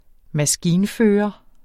Udtale [ maˈsgiːnˌføːʌ ]